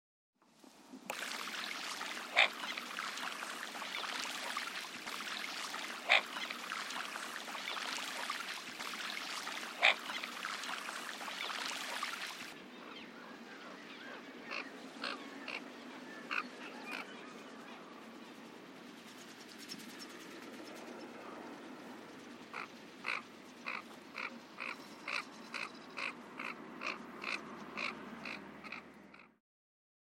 garrot-oeil-d-or.mp3